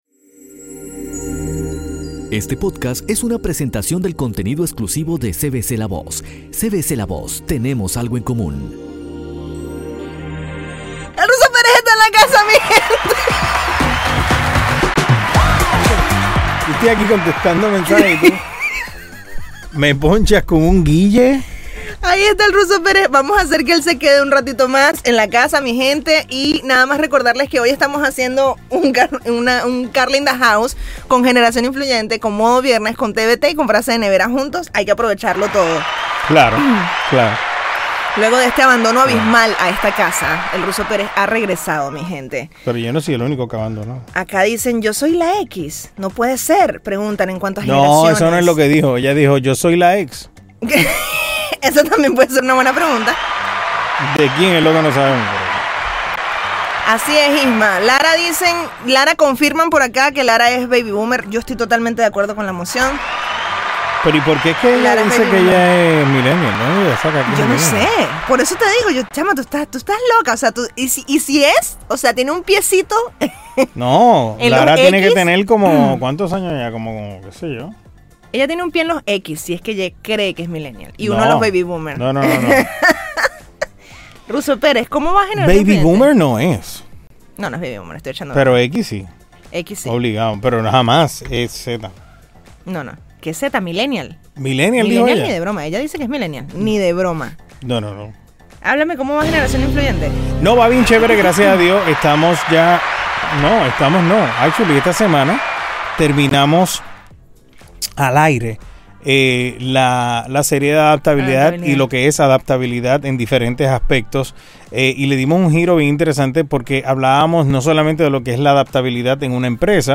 ¿El mentor elige al mentoreado o es al revés? Disfruta de una conversación imperdible